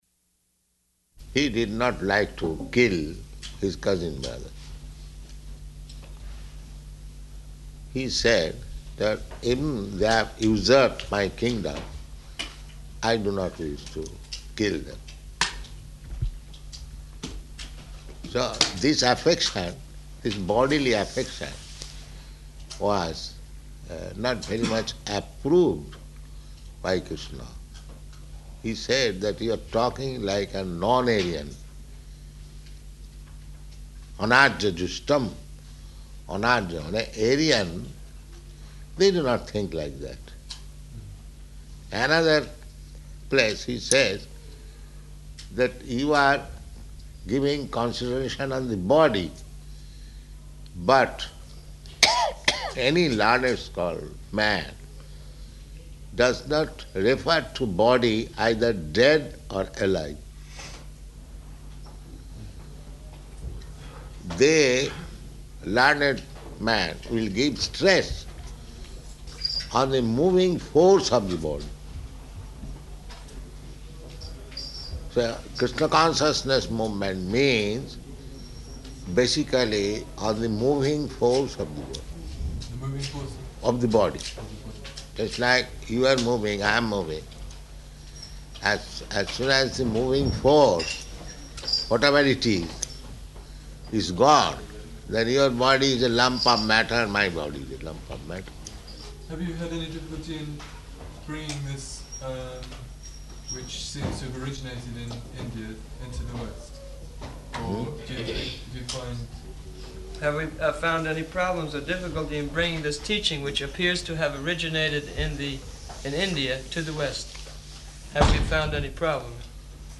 Room Conversation with Reporter
Room Conversation with Reporter --:-- --:-- Type: Conversation Dated: March 9th 1975 Location: London Audio file: 750309R1.LON.mp3 Prabhupāda: ...he did not like to kill his cousin-brothers.